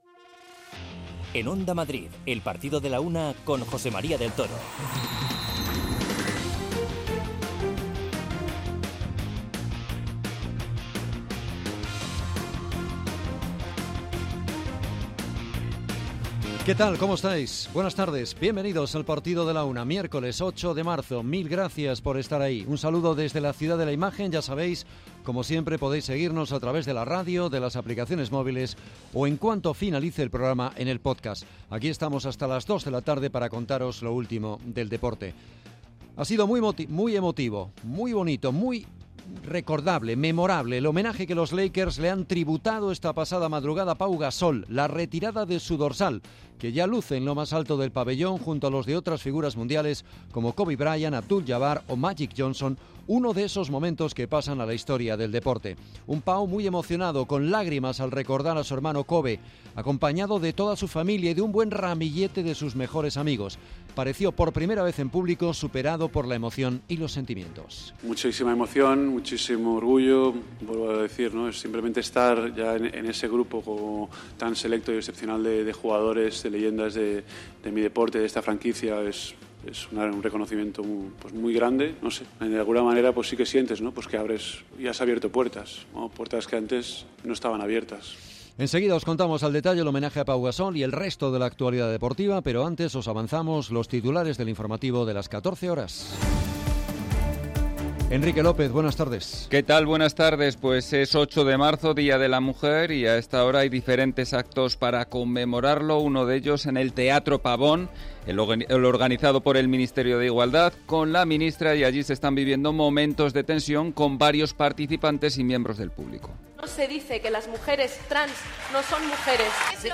Os contamos cómo fue el acto y escuchamos a un emocionado Pau.